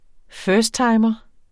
Udtale [ ˈfœːsdˌtɑjmʌ ]